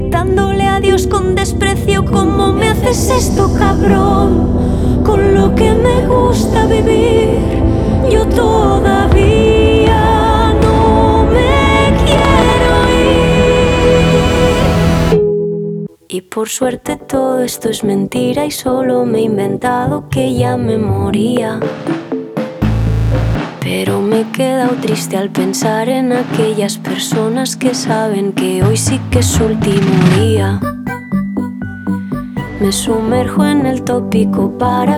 Indie Pop Alternative
Жанр: Поп музыка / Альтернатива